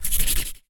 Sfx Crayon Sound Effect
Download a high-quality sfx crayon sound effect.
sfx-crayon-4.mp3